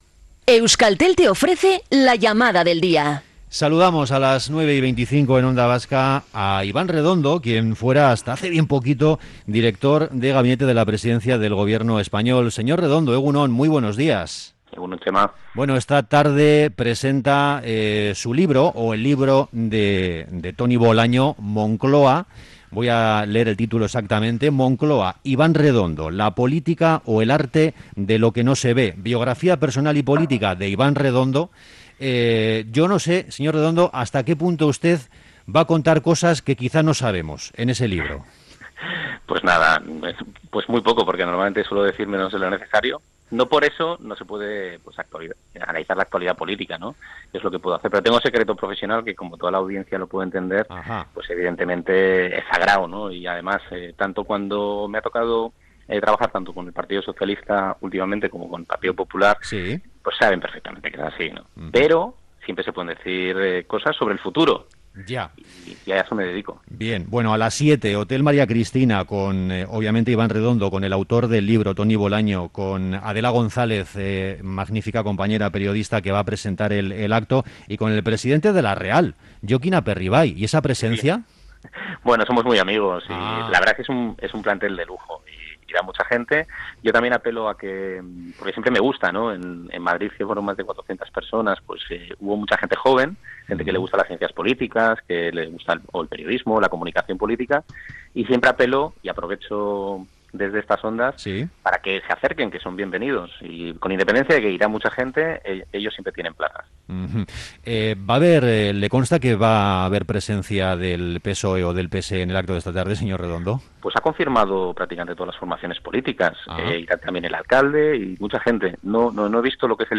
Hablamos con Iván Redondo, ex director de gabinete de la presidencia del Gobierno español - Onda Vasca
Morning show conectado a la calle y omnipresente en la red.